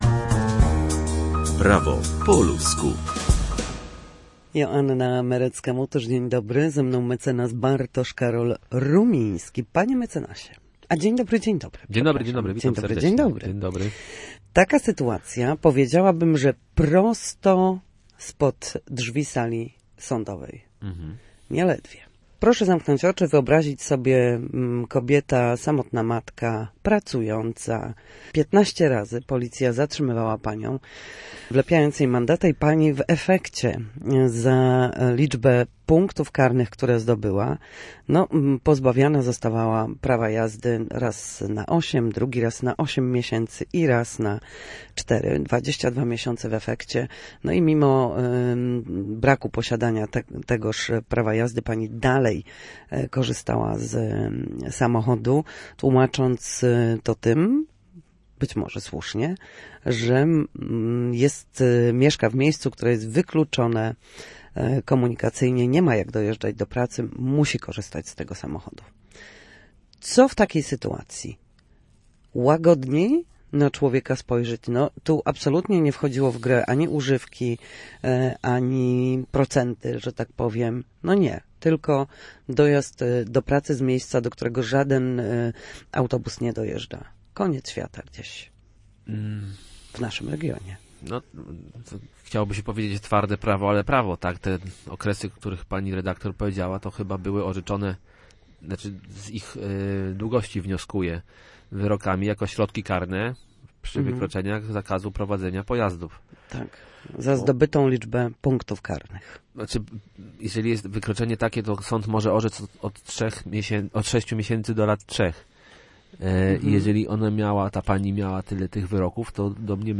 W każdy wtorek o godzinie 13:40 na antenie Studia Słupsk przybliżamy państwu meandry prawa. Nasi goście, prawnicy, odpowiadać będą na jedno pytanie dotyczące zachowania w sądzie czy podstawowych zagadnień prawniczych.